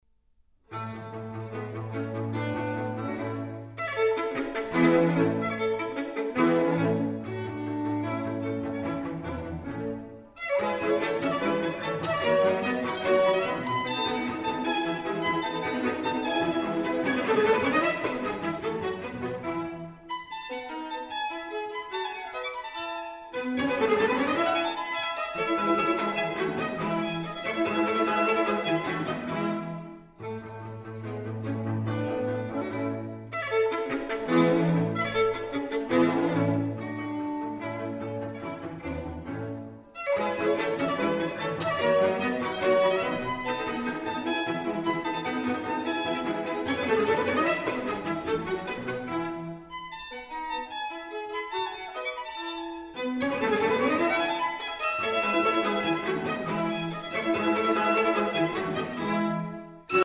A major